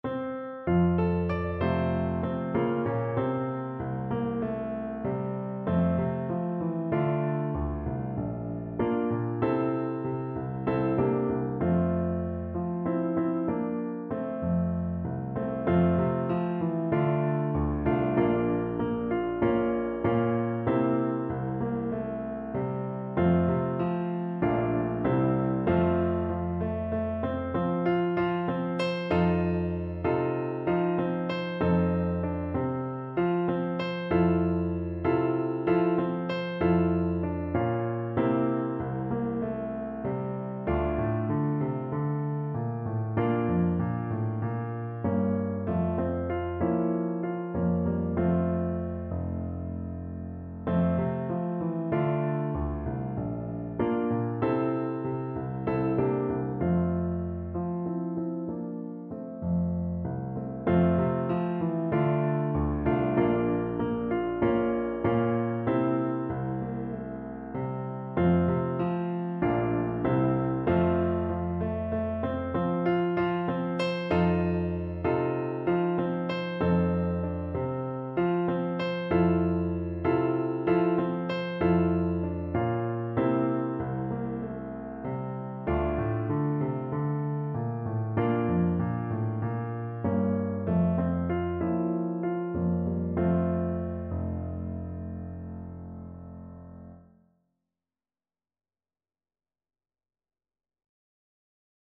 Play (or use space bar on your keyboard) Pause Music Playalong - Piano Accompaniment Playalong Band Accompaniment not yet available transpose reset tempo print settings full screen
Voice
F major (Sounding Pitch) (View more F major Music for Voice )
Moderato espressivo =c.96
4/4 (View more 4/4 Music)
Traditional (View more Traditional Voice Music)